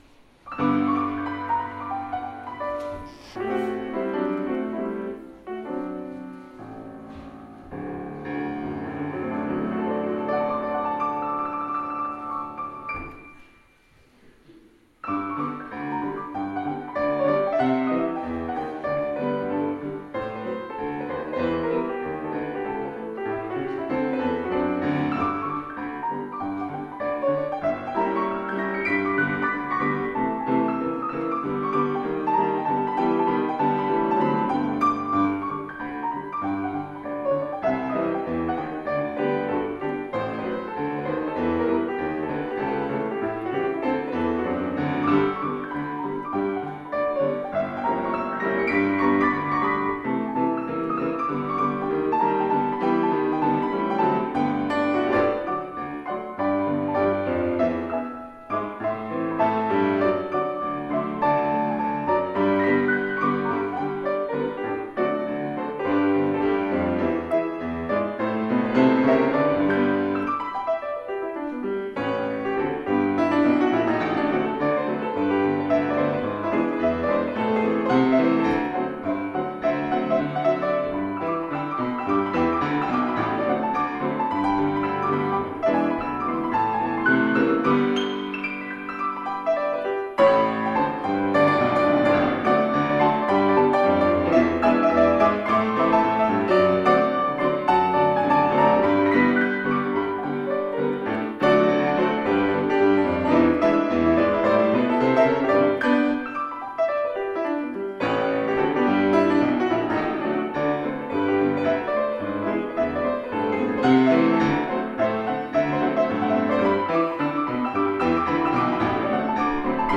[Fuusm-l] A few boisterous pieces for balance
some rowdier tunes.